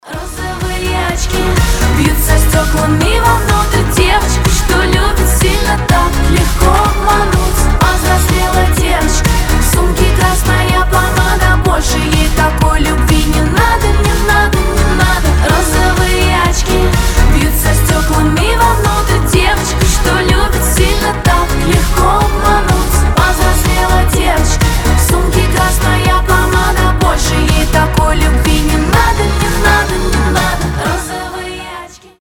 заводные
женский голос